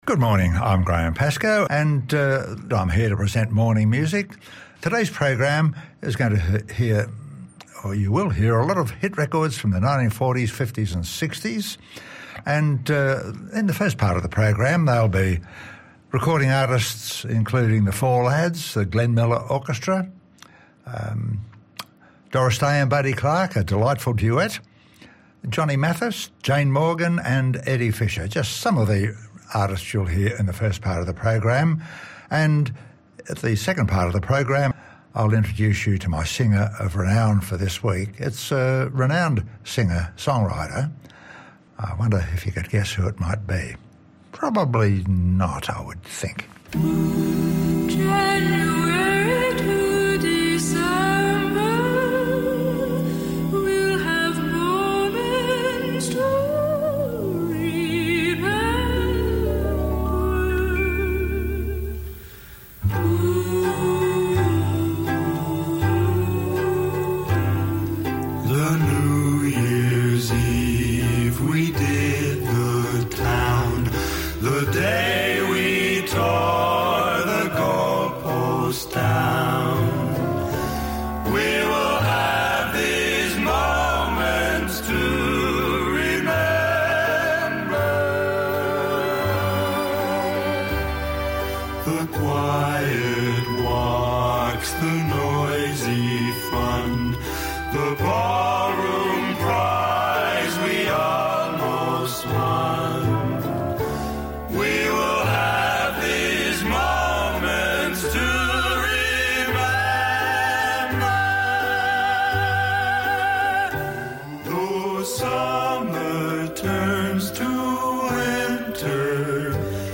popular music from pre rock & roll eras